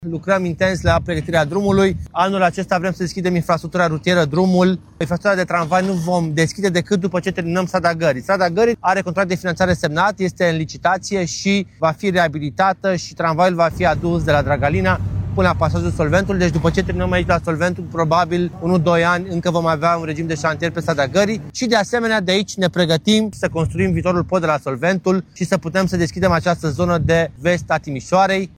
Viceprimarul Timișoarei, Ruben Lațcău, spune că circulația tramvaielor se va deschide doar după finalizarea lucrărilor de pe strada Gării.